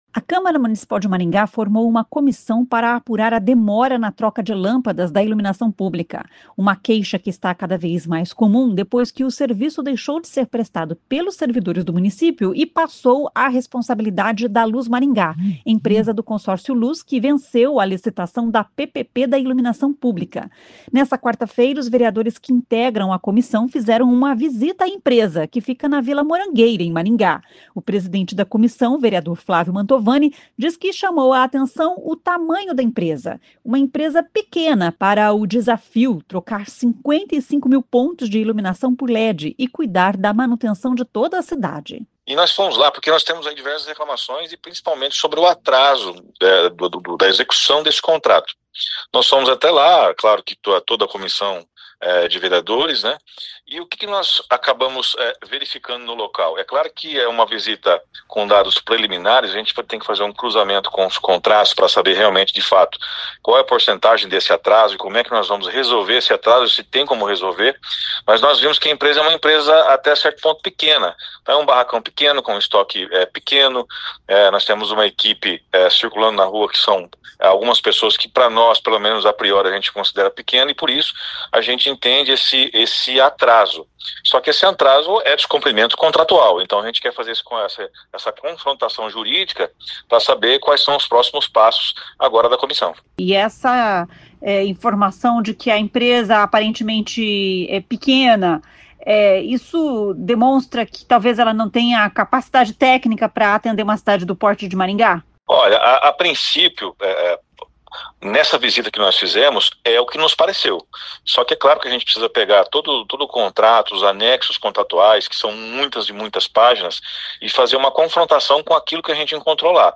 O presidente da Comissão, vereador Flávio Mantovani, diz que chamou a atenção o tamanho da empresa.